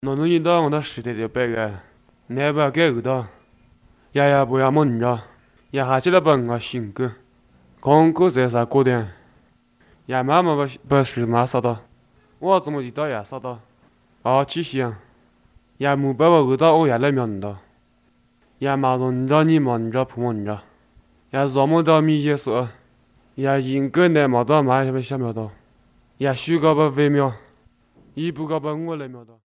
Most use a storytelling approach. These are recorded by mother-tongue speakers